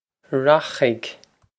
Rachaidh Rokh-ig
Pronunciation for how to say
This is an approximate phonetic pronunciation of the phrase.